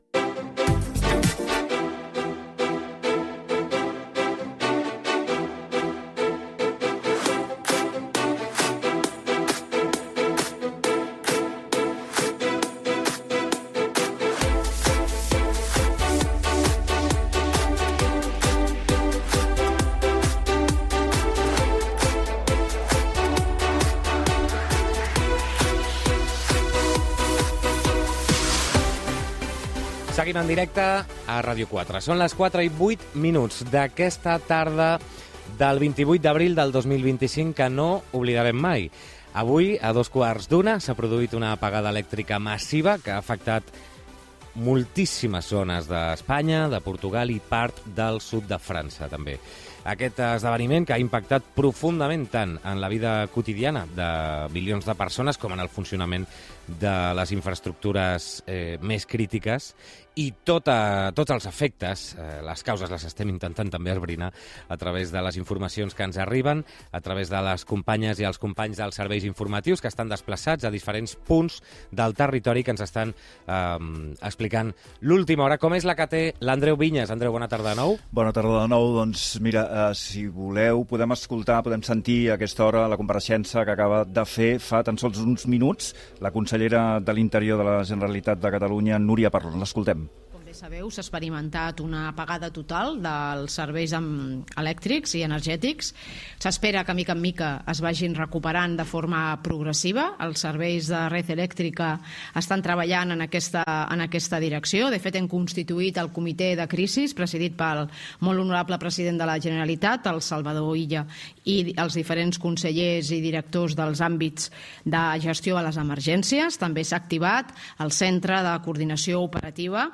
Compareixença de la Consellera d'Interior de la Generalitat Núria Parlon, situació dels trens, metros i dels autobusos.
Gènere radiofònic Entreteniment
Banda FM